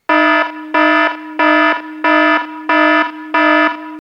new_order_alarm.mp3